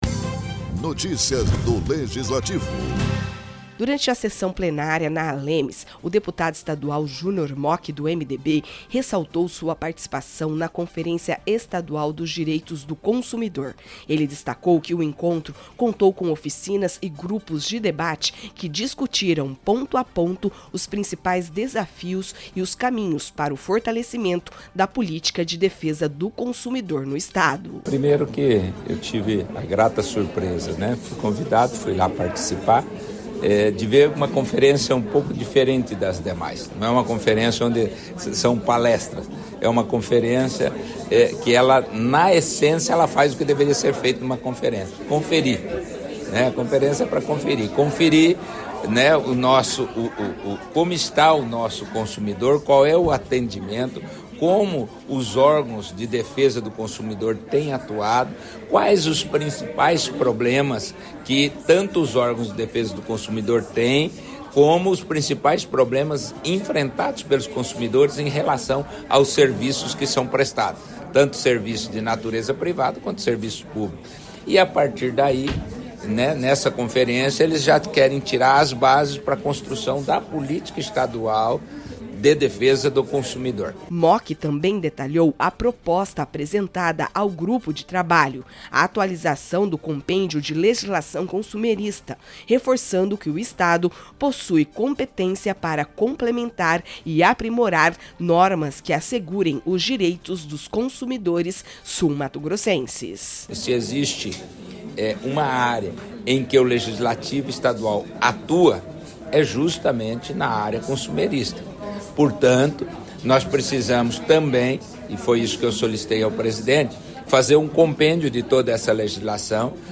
Durante a sessão plenária da Assembleia Legislativa, o deputado Junior Mochi destacou sua participação na Conferência Estadual dos Direitos do Consumidor. O parlamentar reforçou a importância dos debates realizados no evento, que reuniu especialistas e instituições para aprimorar a proteção ao consumidor.